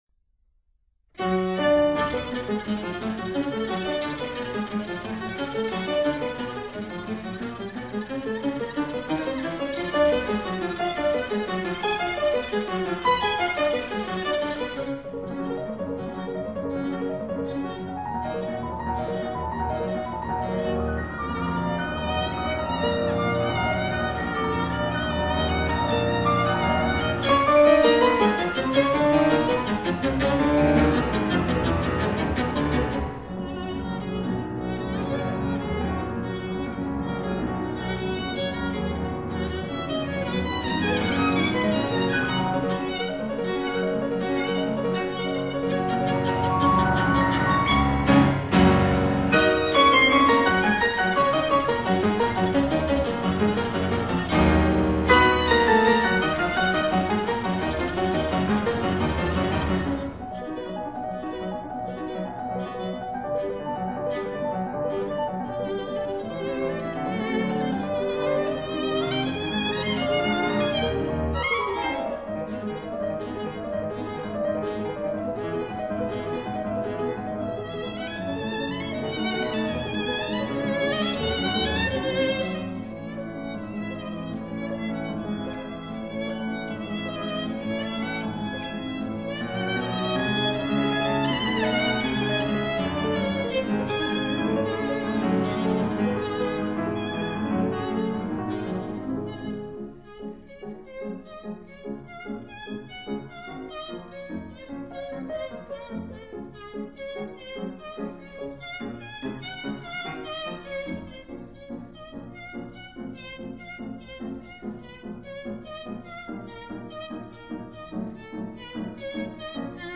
Violin Sonata in F sharp minor
Cello Sonata in E major
•(08) Piano Trio in G minor, Op. 30
violin
cello
piano